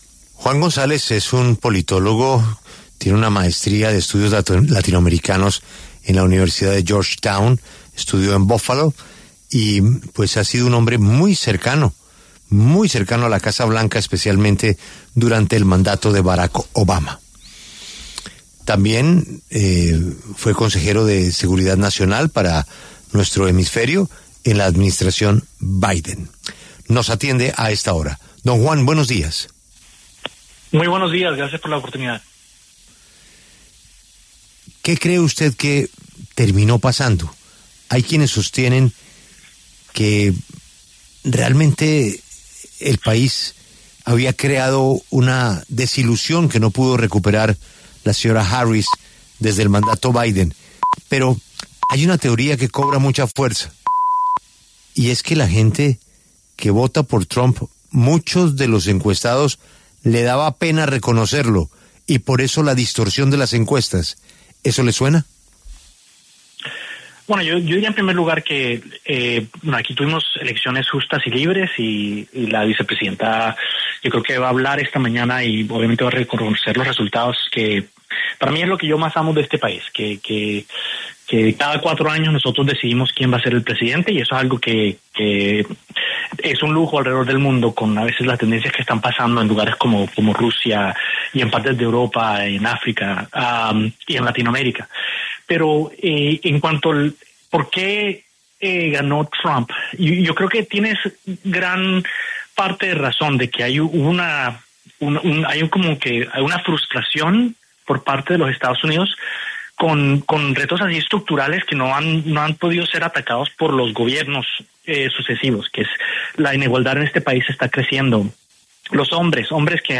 Juan Sebastián González, colombiano exasesor del Gobierno Biden, conversó con La W sobre el triunfo de Donald Trump y lo que espera de esta nueva administración en los EE.UU.